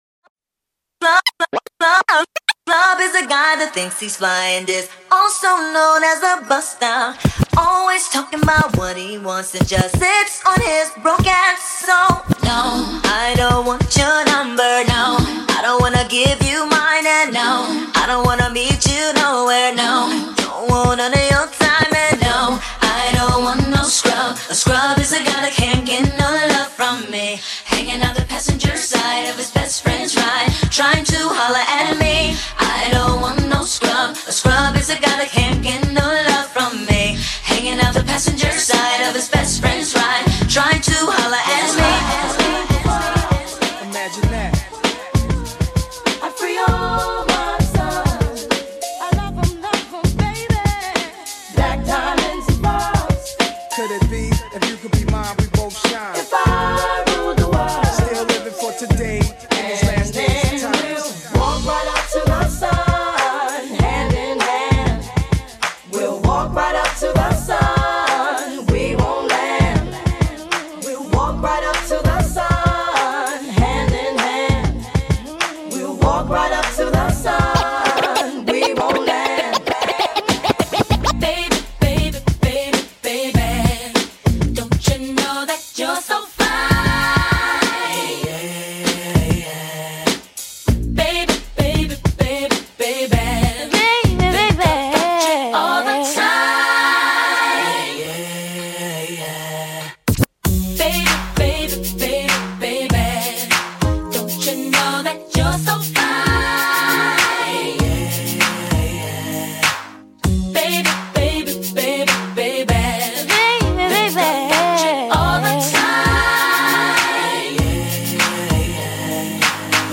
LIVE MIXING & MASHUPS